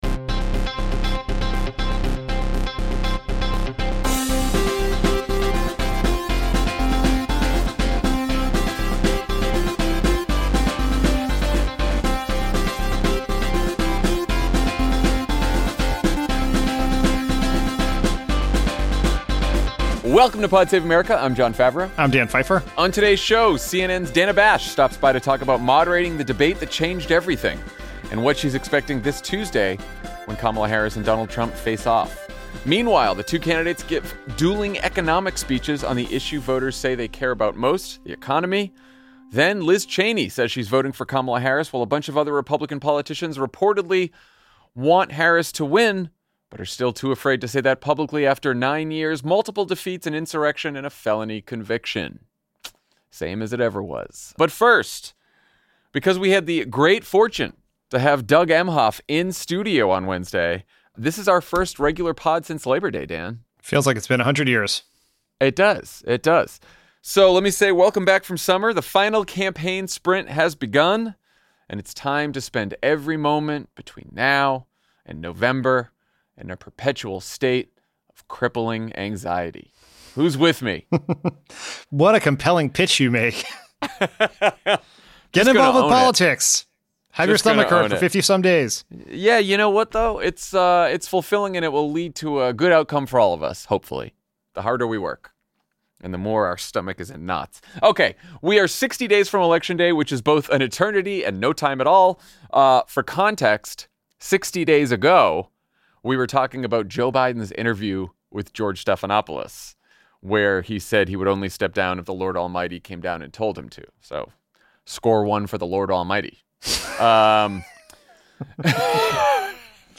Then, CNN’s Dana Bash joins the show to talk about the debate that changed everything and what to expect in next week’s big face-off.